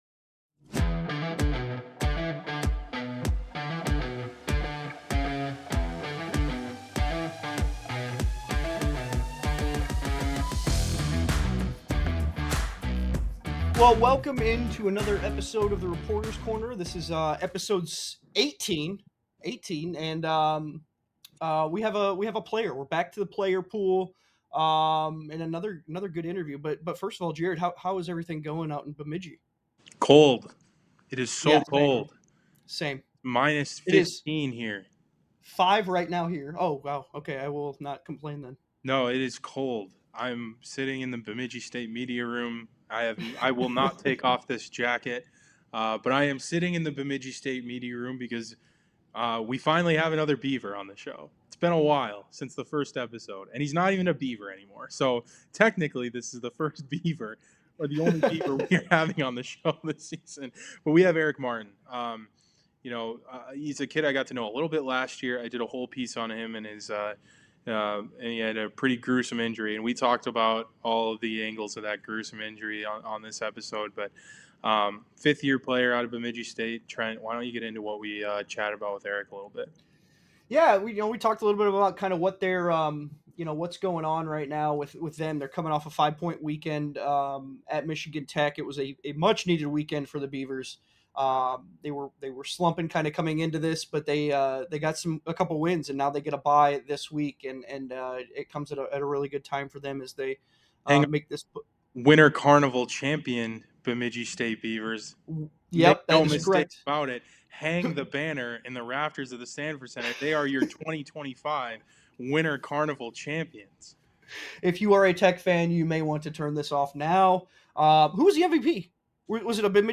Tune in weekly to hear the guys chat it up with CCHA players, coaches and staff!